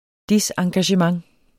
Udtale [ ˈdisɑŋgaɕəmɑŋ ]